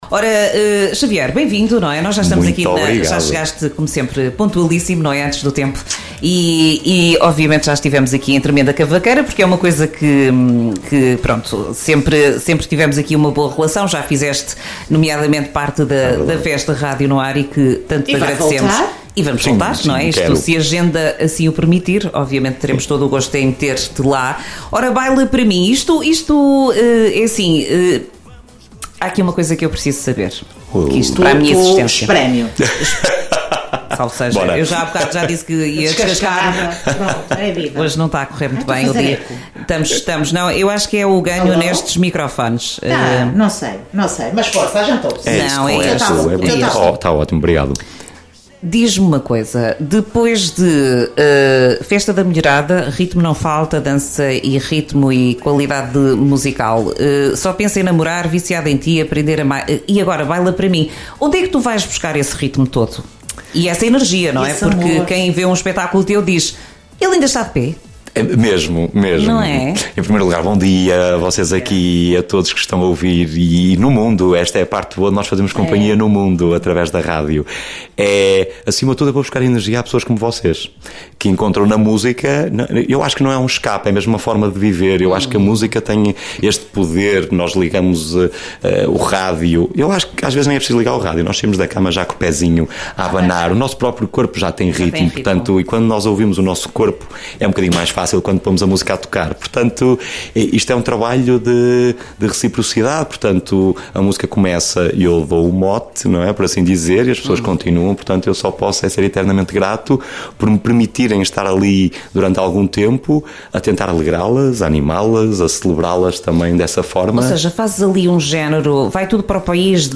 em direto no programa Manhãs NoAr
Entrevista